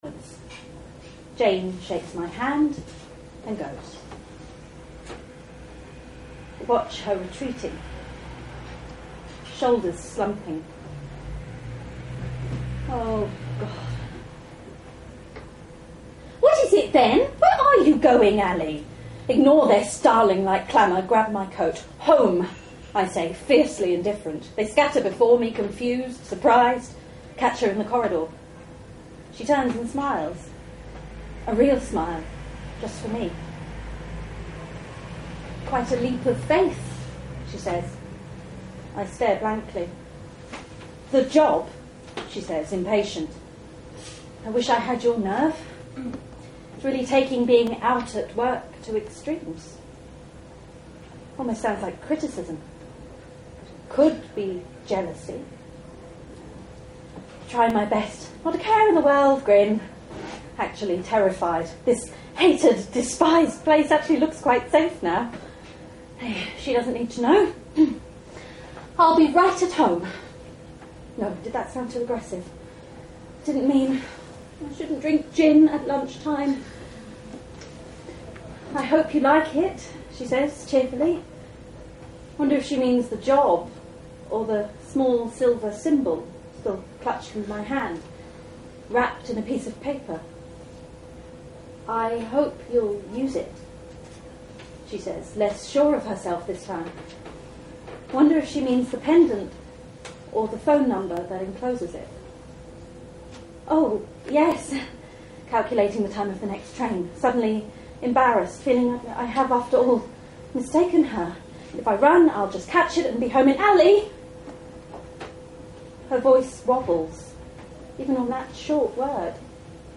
A collection of recordings of me (or actors) performing my stories live to audiences.